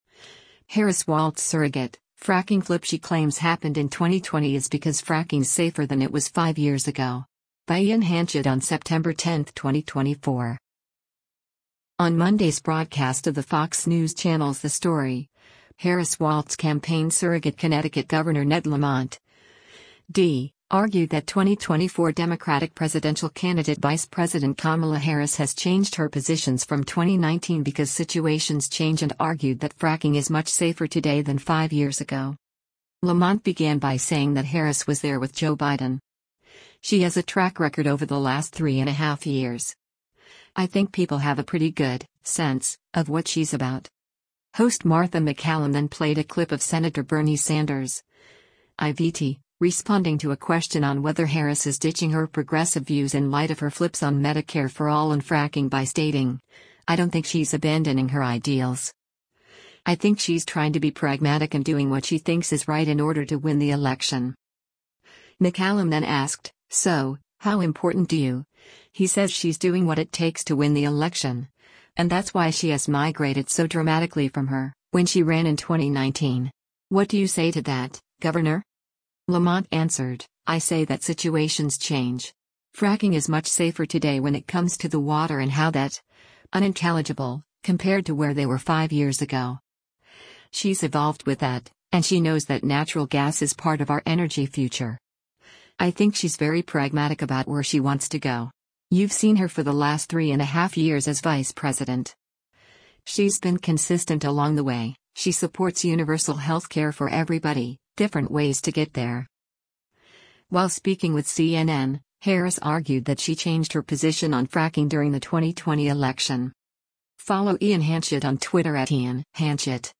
On Monday’s broadcast of the Fox News Channel’s “The Story,” Harris-Walz Campaign surrogate Connecticut Gov. Ned Lamont (D) argued that 2024 Democratic presidential candidate Vice President Kamala Harris has changed her positions from 2019 because “situations change” and argued that “Fracking is much safer today” than five years ago.
Host Martha MacCallum then played a clip of Sen. Bernie Sanders (I-VT) responding to a question on whether Harris is ditching her progressive views in light of her flips on Medicare for All and fracking by stating, “I don’t think she’s abandoning her ideals. I think she’s trying to be pragmatic and doing what she thinks is right in order to win the election.”